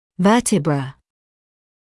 [‘vɜːtɪbrə][‘вёːтибрэ]позвонок (мн.ч. vertebrae, vertebras)